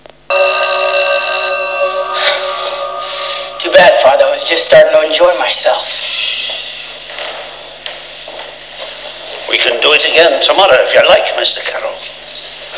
Here are some wav sounds taken from the film